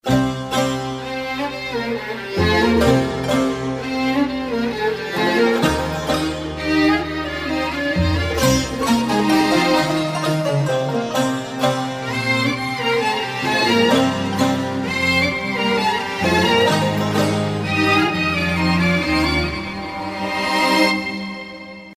رینگتون سنتی ملایم بی کلام